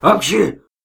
Звук обнаружения вируса в Антивирусе Касперского (похоже на визг свиньи)